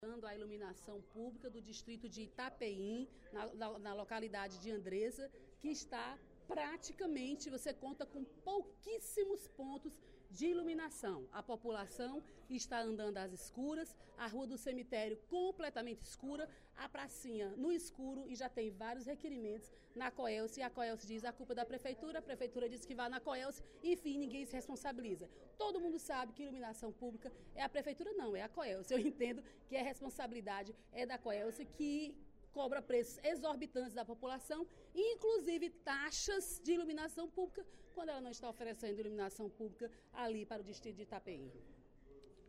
A deputada Dra. Silvana (PMDB) lamentou, durante o primeiro expediente da sessão plenária desta quinta-feira (27/08), a ausência de serviços de iluminação pública no distrito de Itapeí, na localidade de Andreza, no município de Beberibe. Segundo a parlamentar, o único ponto de luz da localidade vem da igreja, e é responsabilidade da Companhia Energética do Ceará (Coelce) resolver o problema.